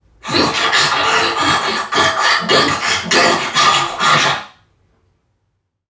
NPC_Creatures_Vocalisations_Robothead [74].wav